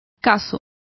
Complete with pronunciation of the translation of dipper.